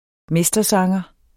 mestersanger substantiv, fælleskøn Bøjning -en, -e, -ne Udtale [ ˈmεsdʌˌsɑŋʌ ] Betydninger 1.